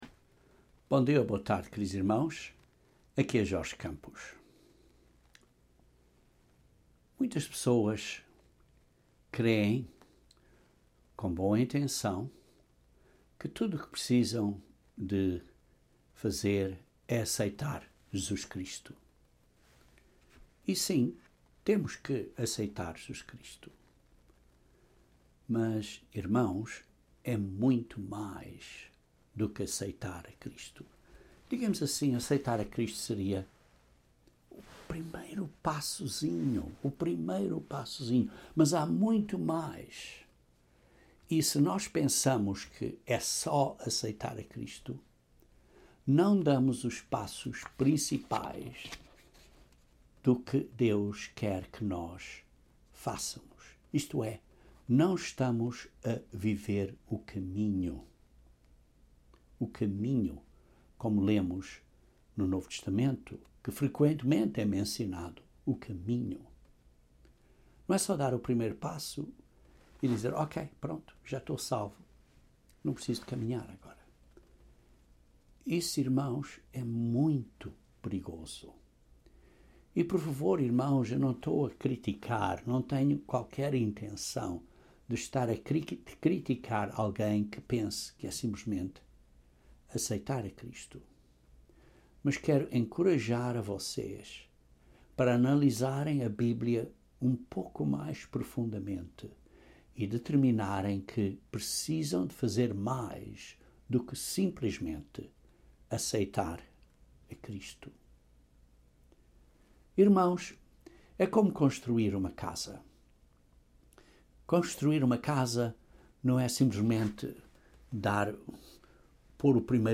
Este sermão aborda os ensinamentos básicos de Jesus Cristo e nos encoraja a crescer em direção à maturidade para podermos entrar no Reino de Deus, e assim nos qualificarmos para governar sob Cristo.